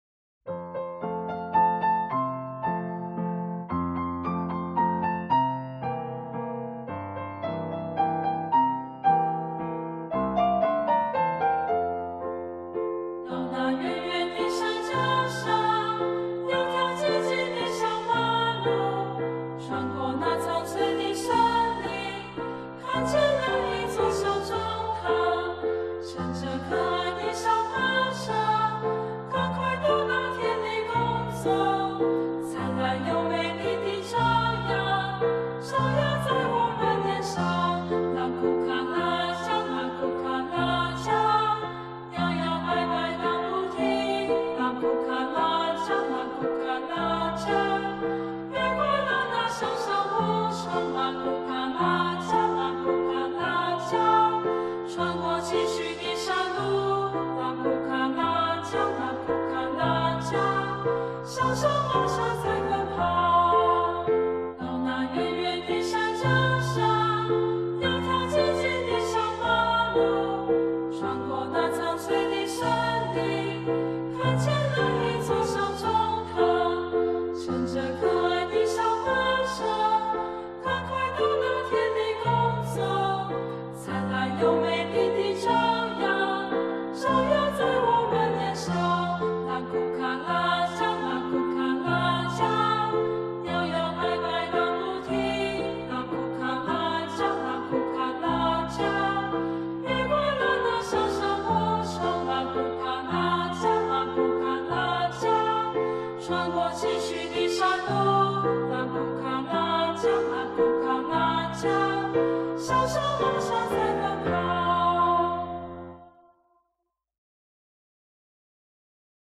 20-乘著馬車盪遊原野-範唱.mp3